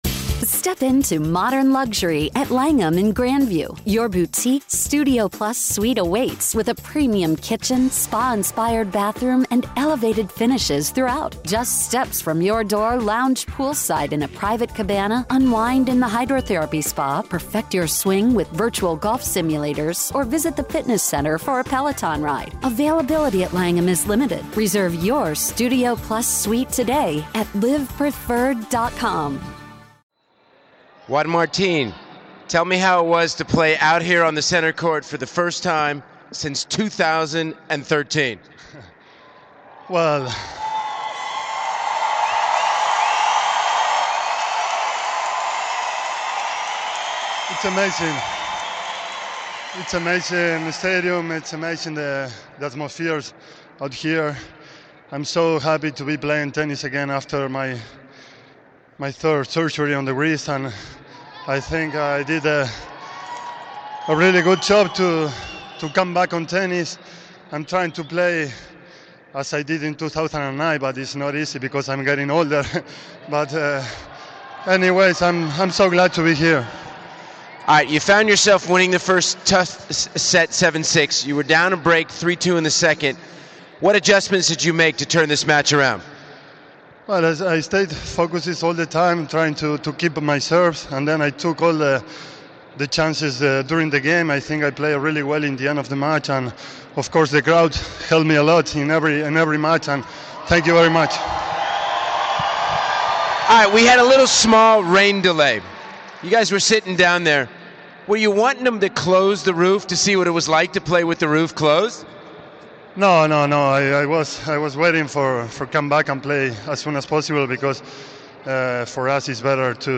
del Potro post match interview